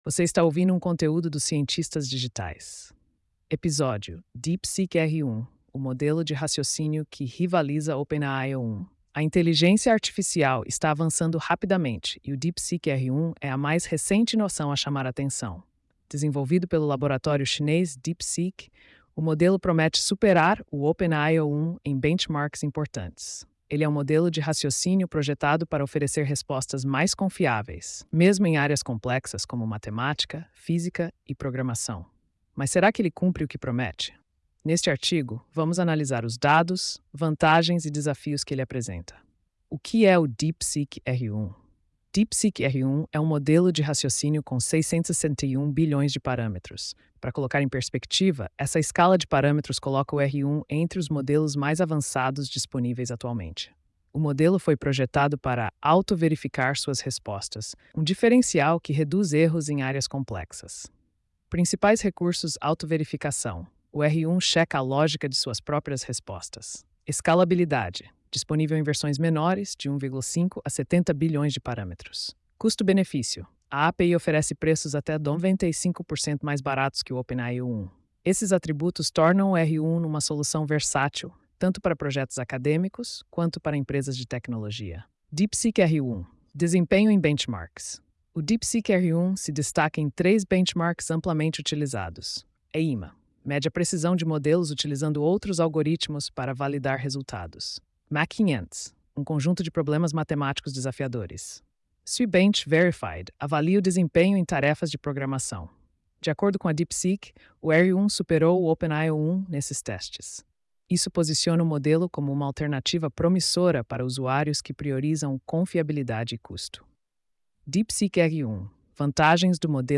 post-2695-tts.mp3